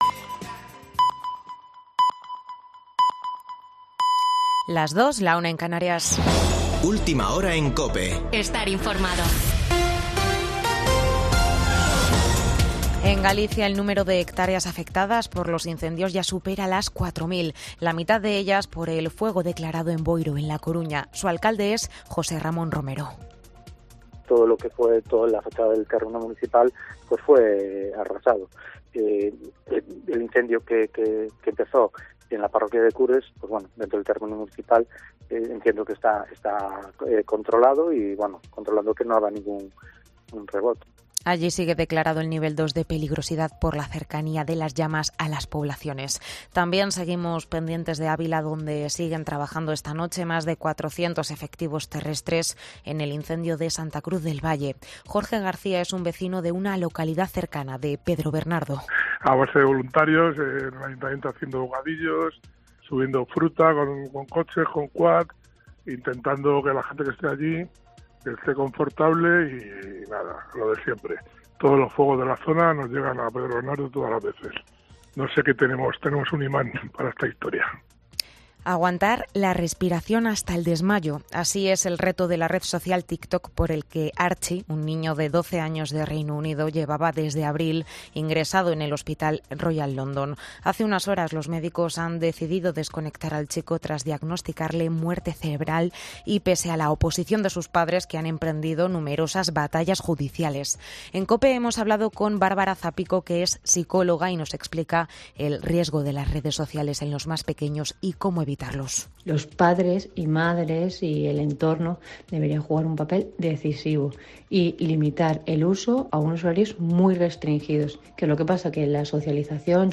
Boletín de noticias de COPE del 7 de agosto de 2022 a las 02.00 horas